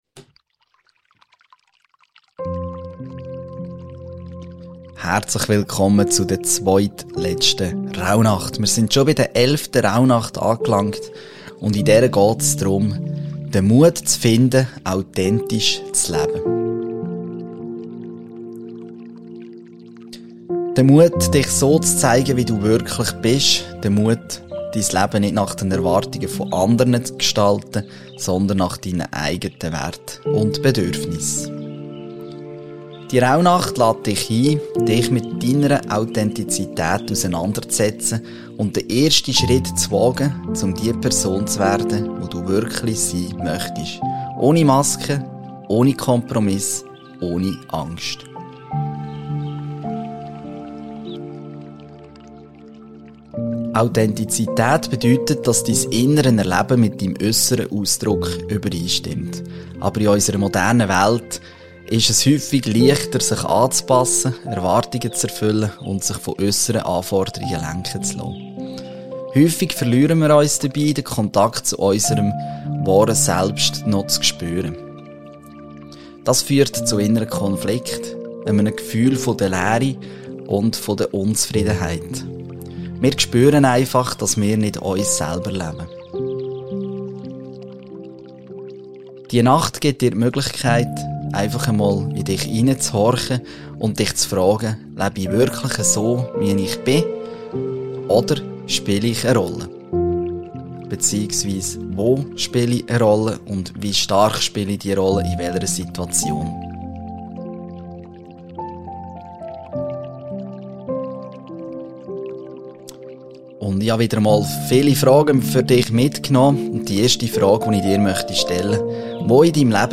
Der Seelefrede Hypnose Podcast für hochsensible Menschen
Die im Podcast verwendete Musik stammt von